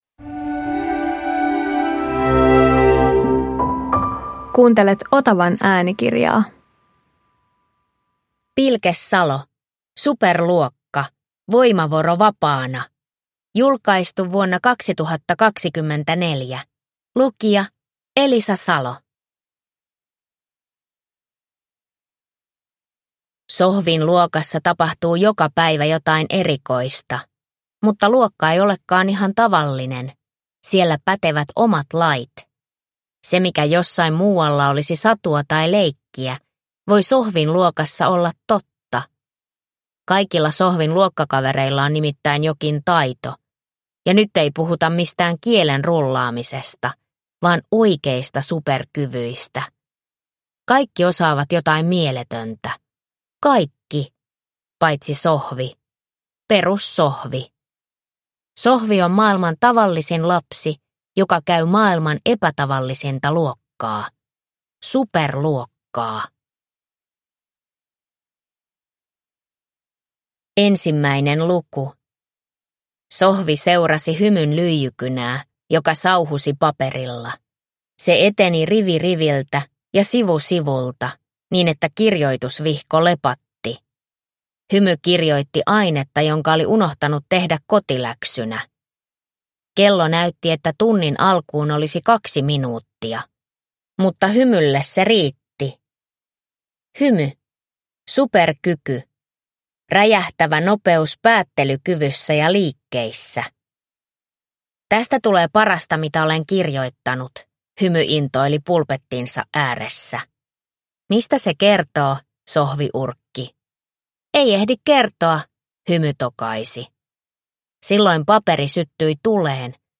Voimavoro vapaana – Ljudbok